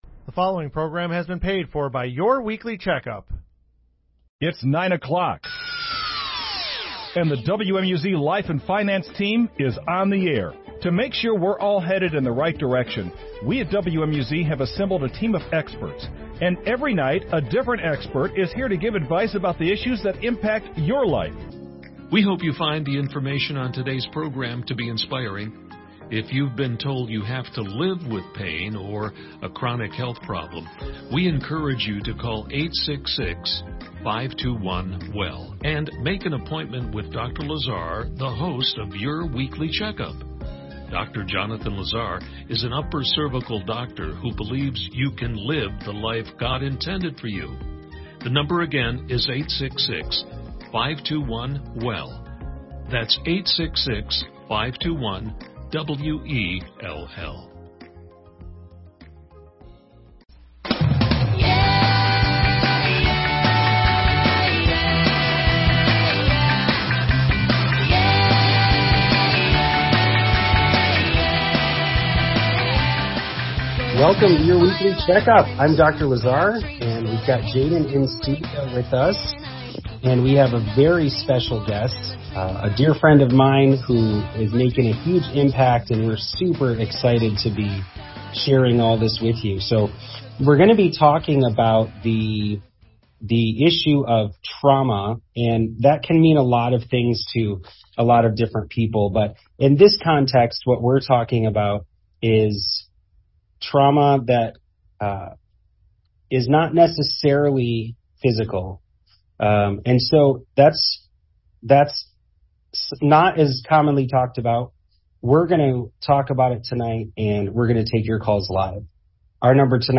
Your Weekly Checkup is a call-in radio show that brings you the health information you've been looking for.